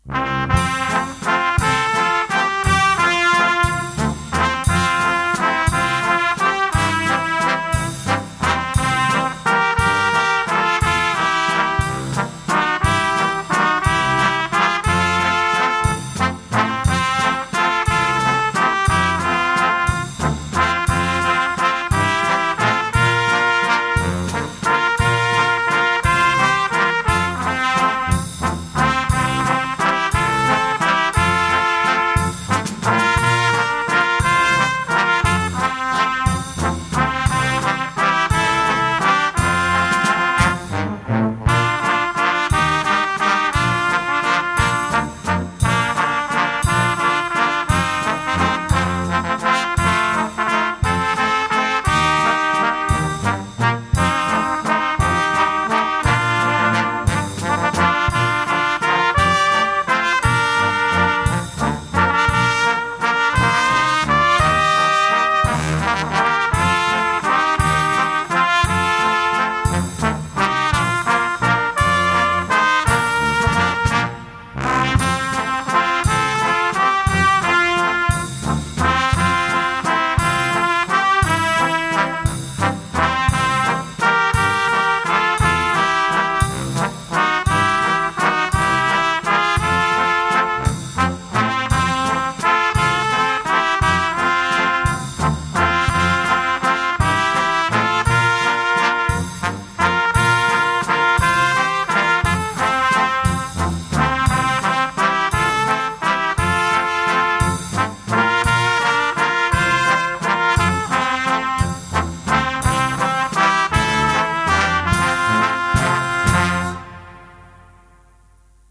seegeisterlied_instrumental.mp3